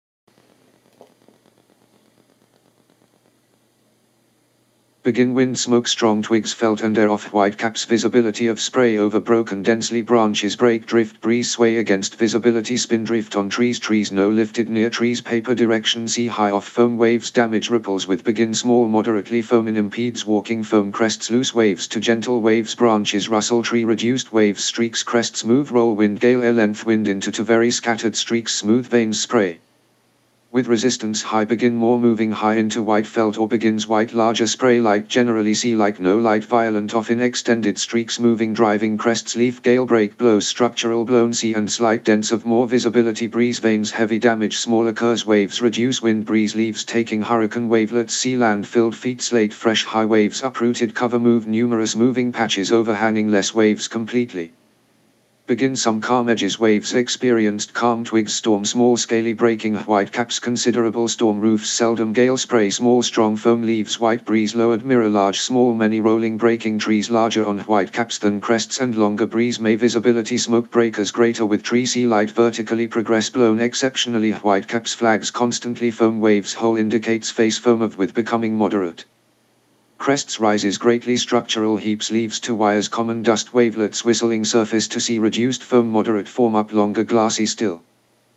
This prompted me to find, through cut-up, a rampant narrated poem of both the ‘Effects on Water’ and ‘Effects on Land’ of this scale.